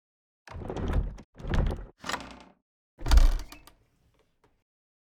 sfx_chest_open_v2.ogg